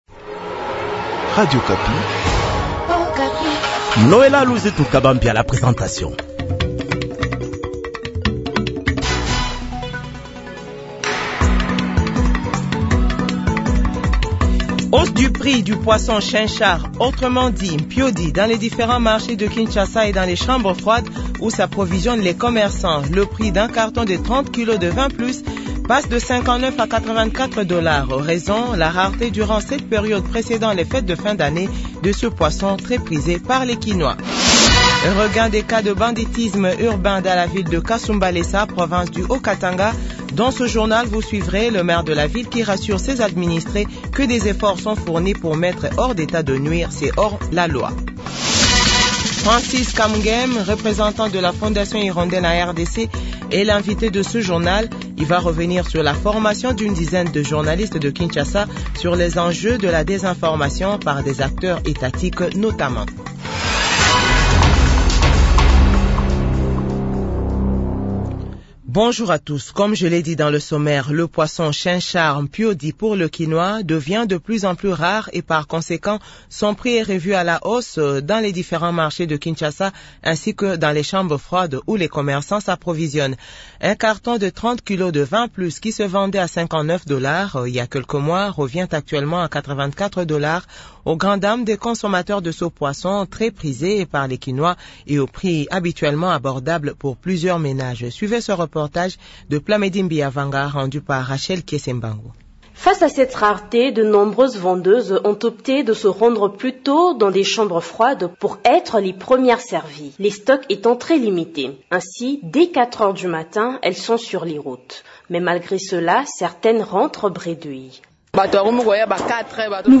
JOURNAL FRANÇAIS DE 6H00 - 7H00